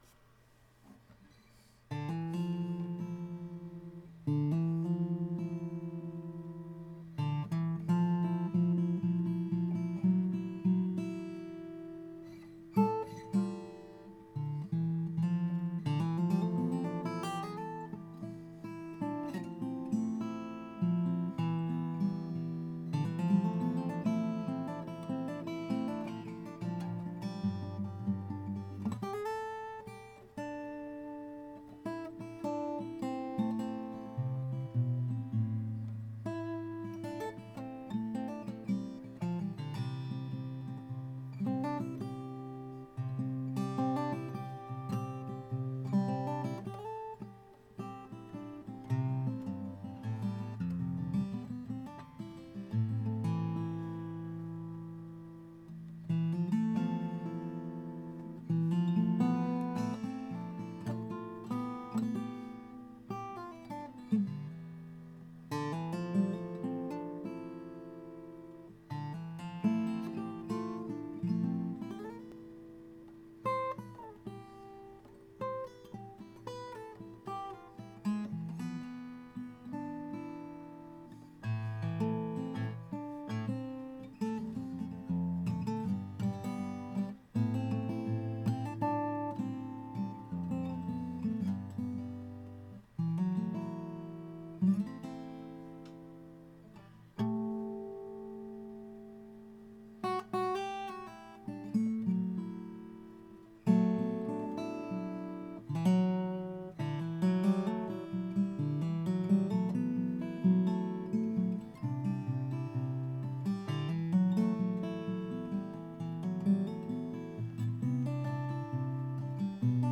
It responds to a very light touch, yet projects like anything when asked to.
From the customer:  "Here is a short bit of stuff I thought captured some of the cedar/rosewood 00 sound"
B00 guitar:  western red cedar top and Indian rosewood back and sides